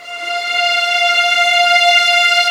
Index of /90_sSampleCDs/Roland LCDP13 String Sections/STR_Violins I/STR_Vls2 Arco